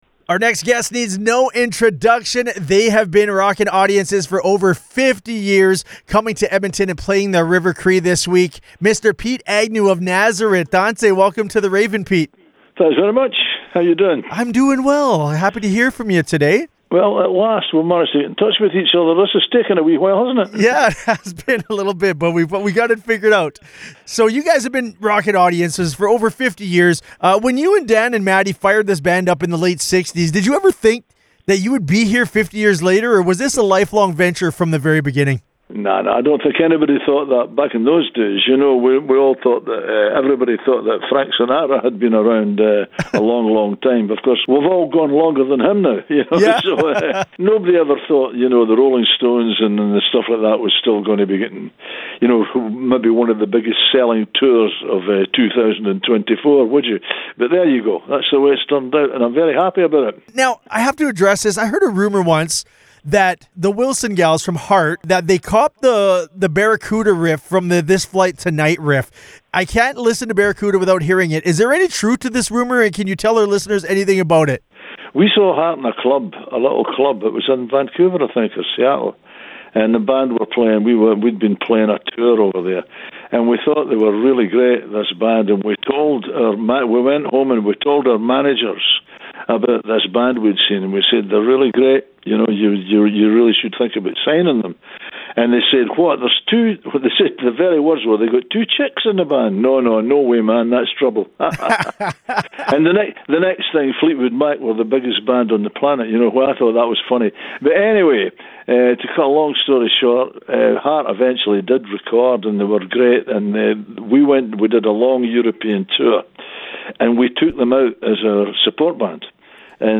pete-nazareth-full-for-web-no-music.mp3